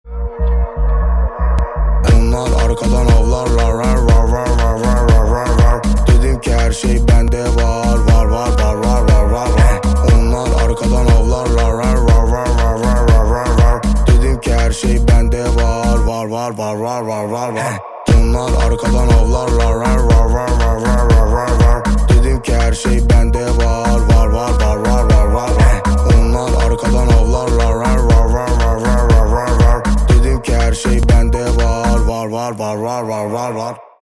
рэп , турецкие , moombahton
танцевальные